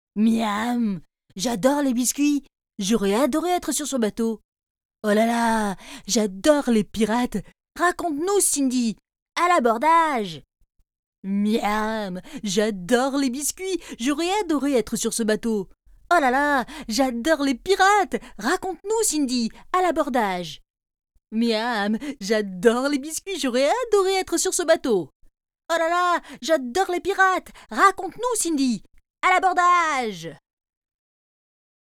I’m a native French voice-over artist and producer with years of experience delivering high-quality voice work.
Gear: I use Ableton Live 11, a MOTU UltraLite interface, and a Blue Baby Bottle microphone for professional-grade sound.
Sprechprobe: Sonstiges (Muttersprache):